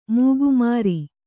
♪ mūgu māri